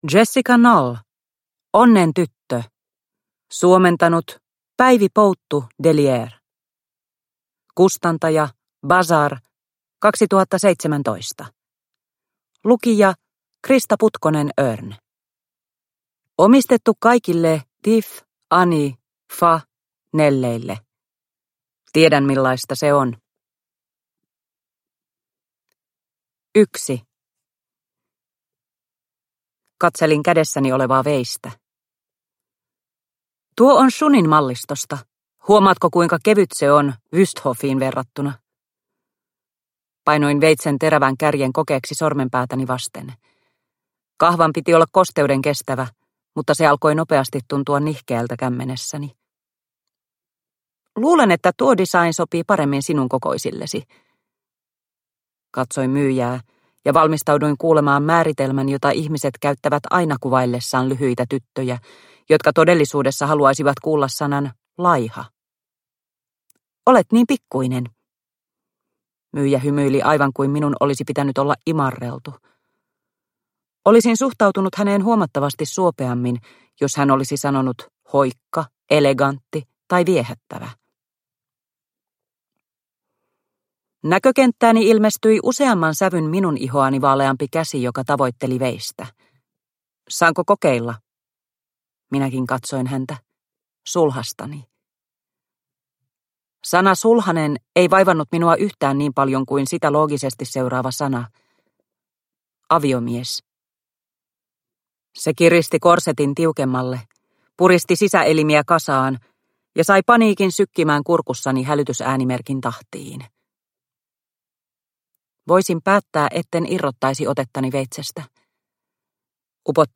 Onnentyttö – Ljudbok – Laddas ner